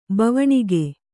♪ bavaṇige